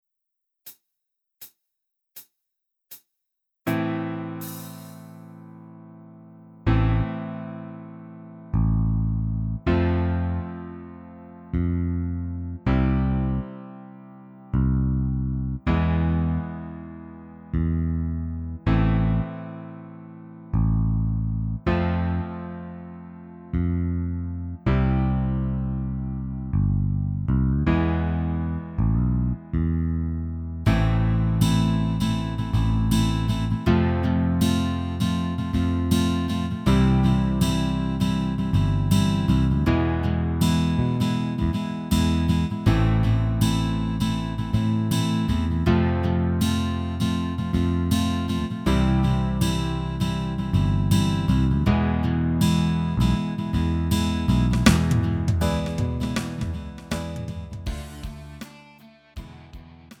음정 원키 3:44
장르 가요 구분 Lite MR
Lite MR은 저렴한 가격에 간단한 연습이나 취미용으로 활용할 수 있는 가벼운 반주입니다.